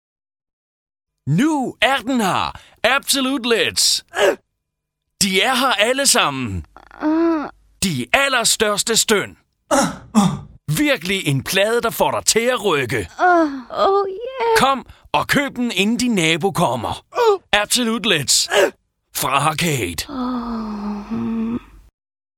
Det er her, du kan høre alle de gode, gamle indslag fra ANR's legendariske satireprogram.
Farlig Fredag hærgede de nordjyske radiobølger op gennem halvfemserne, hvor "Brian-banden", "Dørmændene" og mange andre indslag opnåede kultstatus.